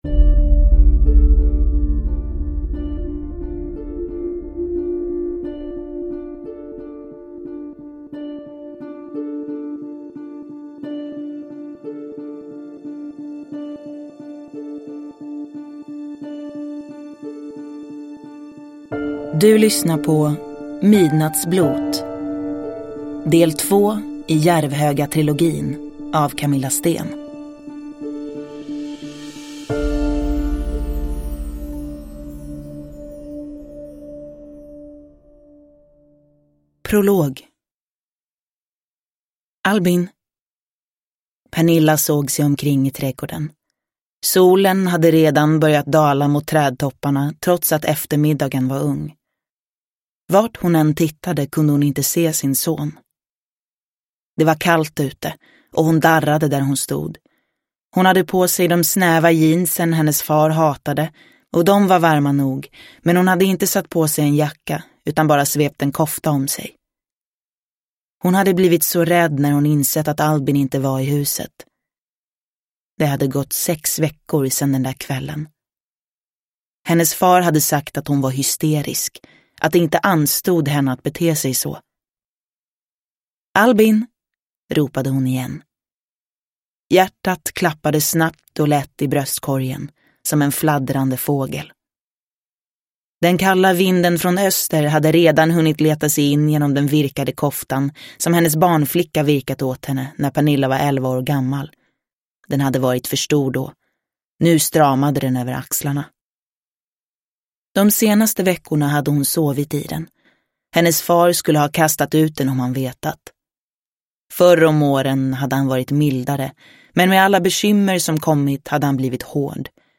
Midnattsblot – Ljudbok – Laddas ner